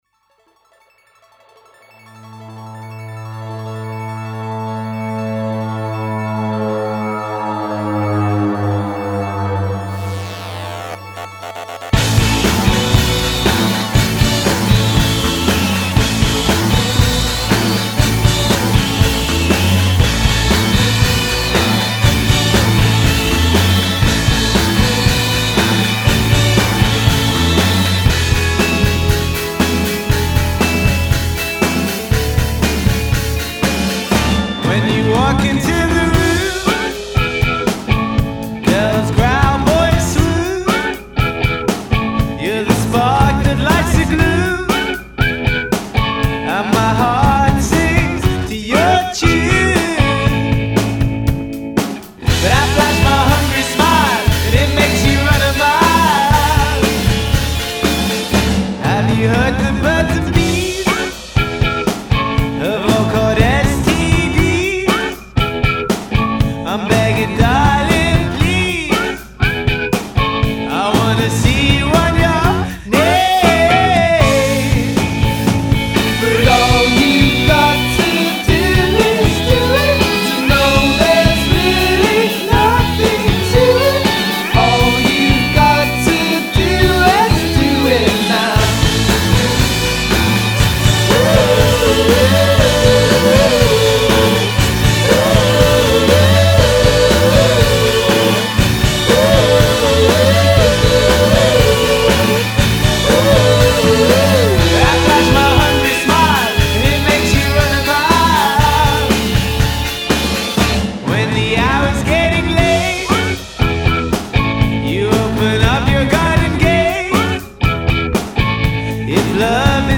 showcases a nice arsenal of catchy hooks